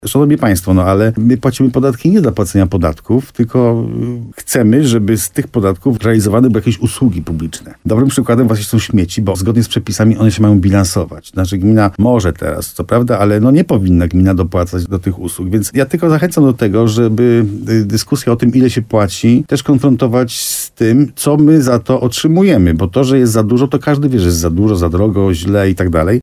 Podwyżka za śmieci w gminie Dobra. Wójt mówi, że to konieczne [ROZMOWA]
Jak tłumaczył w programie Słowo za Słowo w RDN Nowy Sącz wójt Benedykt Węgrzyn, minimalny wzrost jest koniecznością, aby samorząd mógł się rozwijać i planować nowe inwestycje.